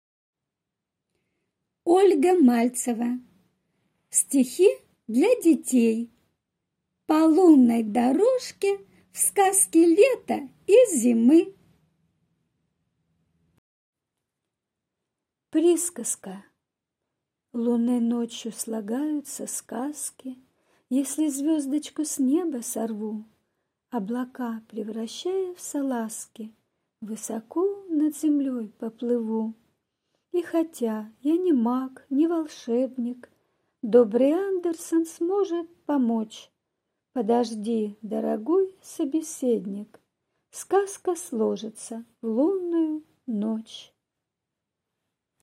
Аудиокнига По лунной дорожке в сказки лета и зимы | Библиотека аудиокниг